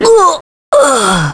Pavel-Vox_Dead_kr.wav